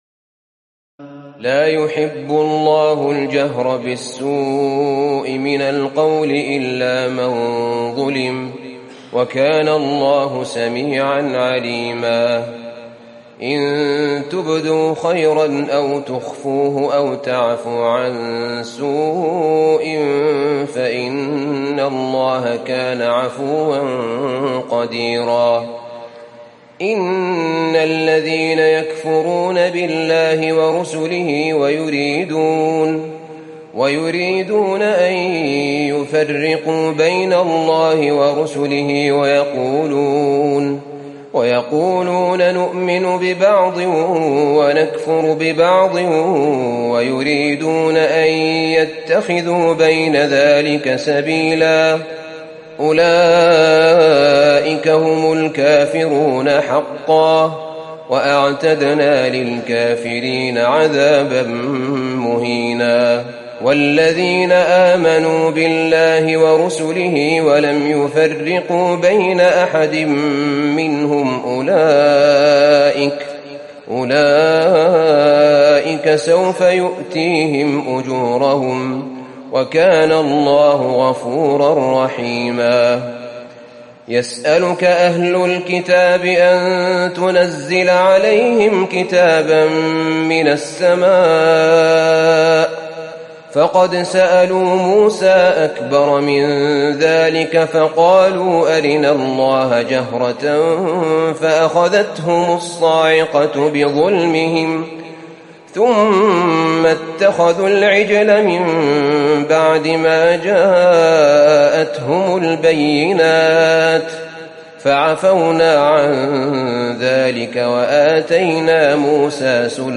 تراويح الليلة السادسة رمضان 1438هـ من سورتي النساء (148-176) و المائدة (1-26) Taraweeh 6 st night Ramadan 1438H from Surah An-Nisaa and AlMa'idah > تراويح الحرم النبوي عام 1438 🕌 > التراويح - تلاوات الحرمين